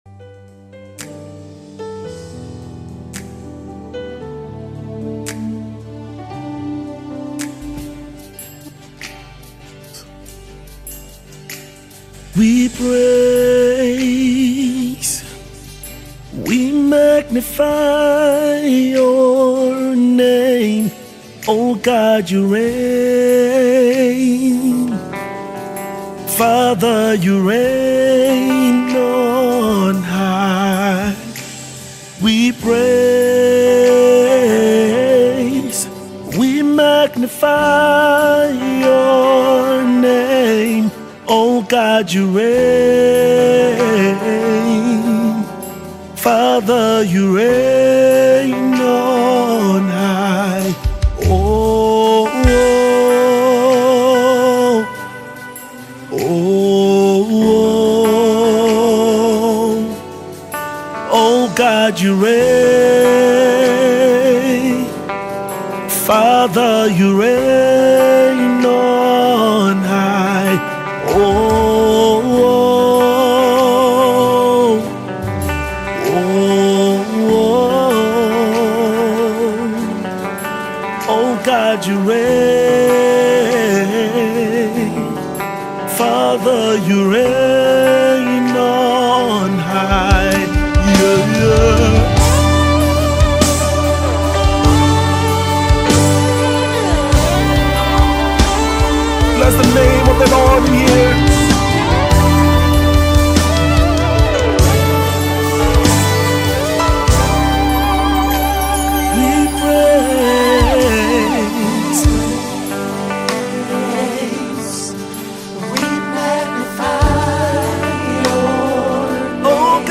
January 17, 2025 Publisher 01 Gospel 0